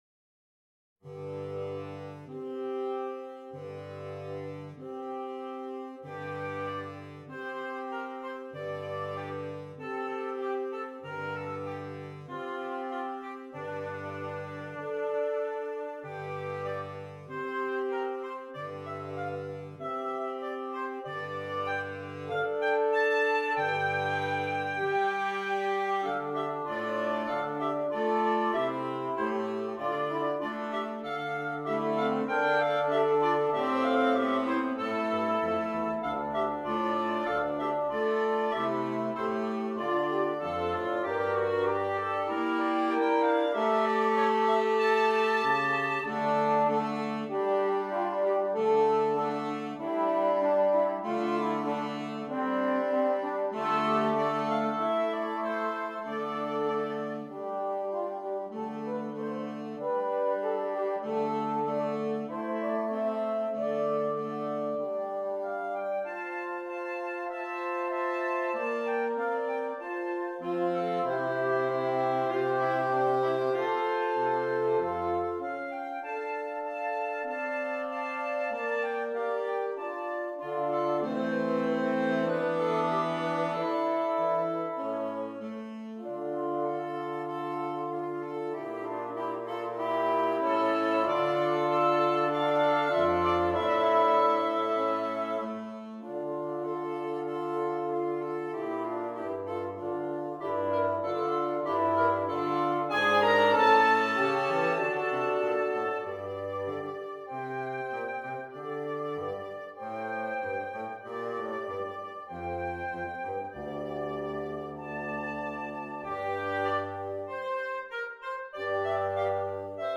Interchangeable Woodwind Ensemble
A pavane was a slow dance form the 16th and 17th century.